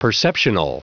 Prononciation du mot perceptional en anglais (fichier audio)
Prononciation du mot : perceptional